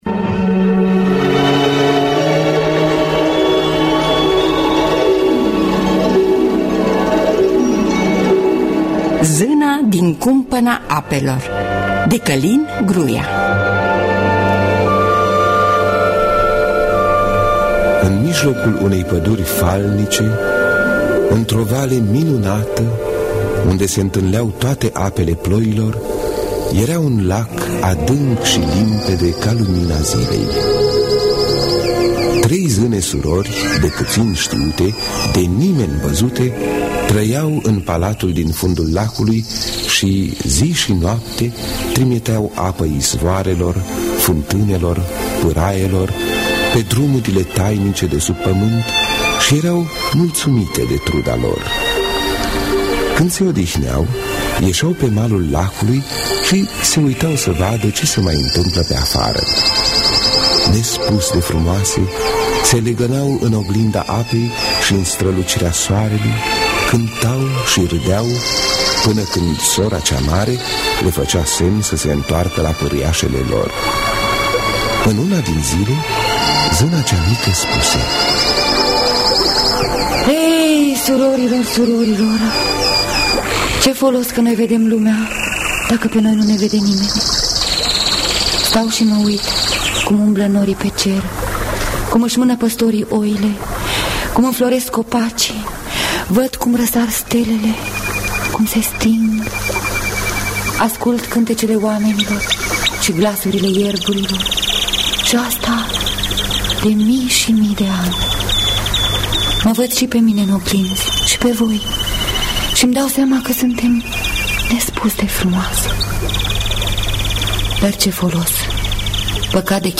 Scenariu radiofonic de Călin Gruia.